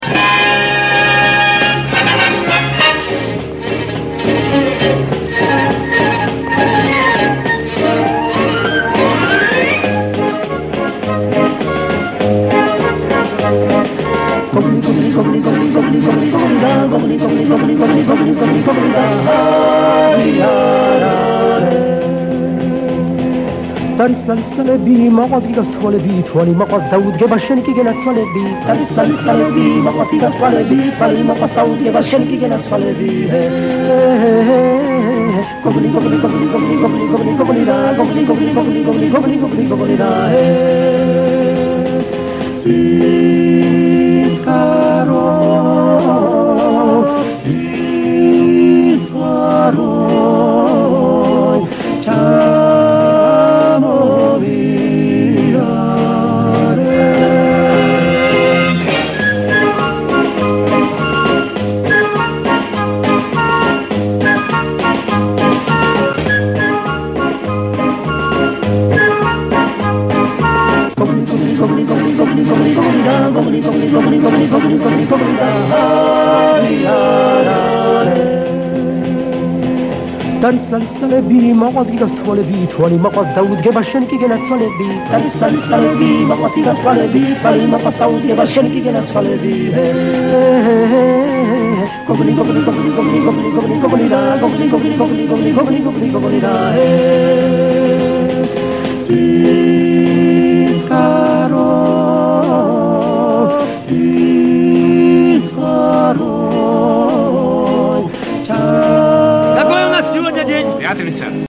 11, фон, МУЗЫКА.